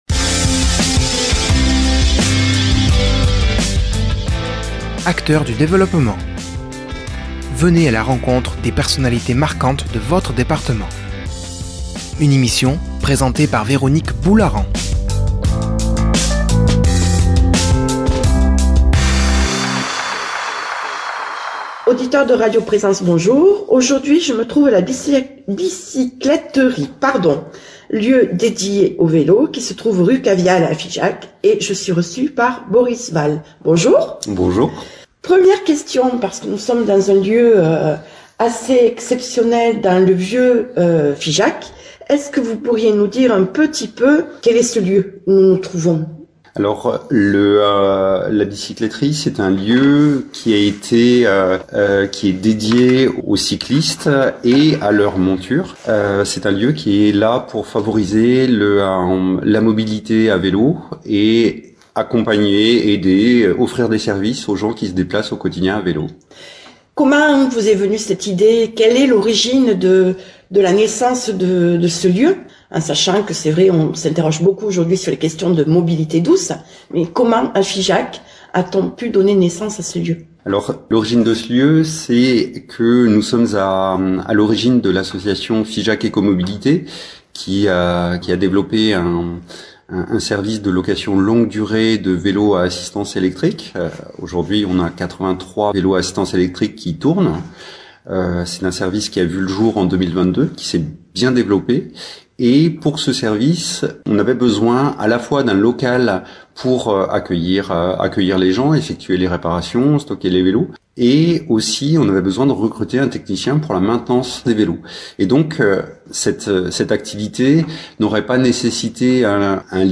dans les rues de Figeac dans un lieu dédié au vélo